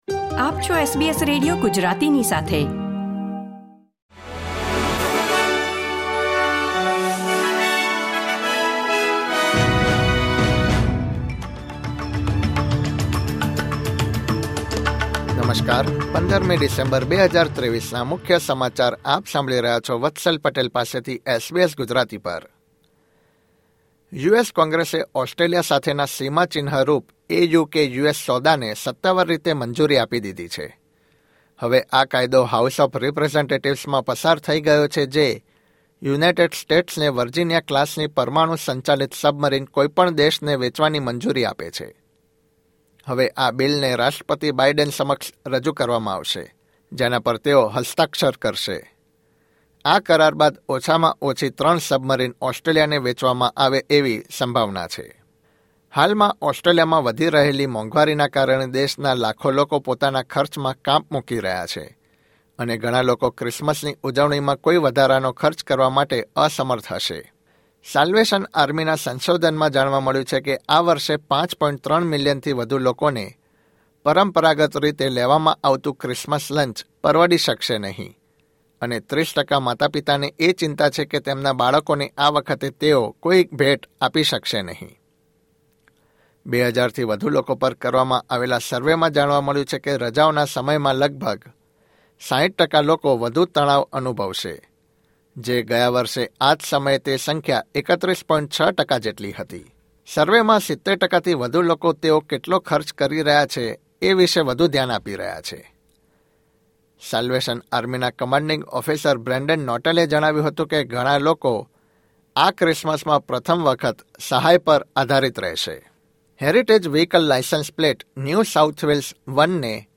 SBS Gujarati News Bulletin 15 December 2023